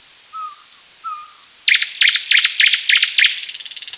weirdbird.mp3